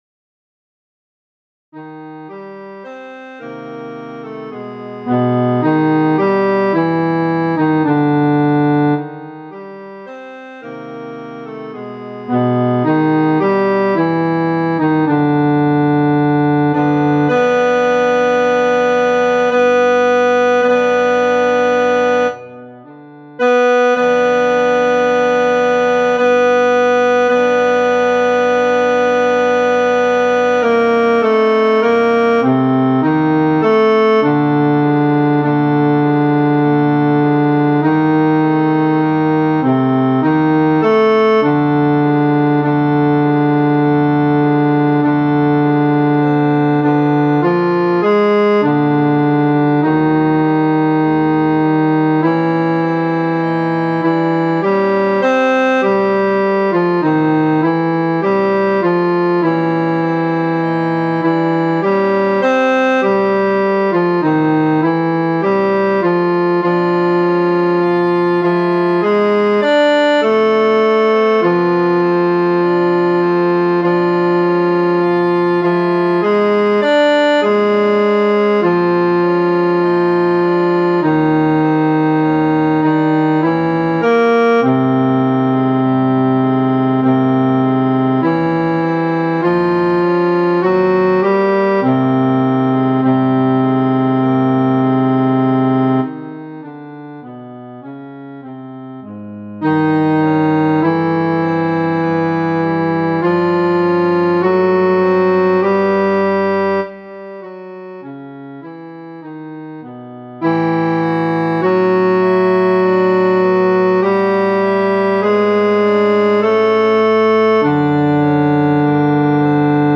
FF:HV_15b Collegium male choir
Zarlivec-Bar-solo.mp3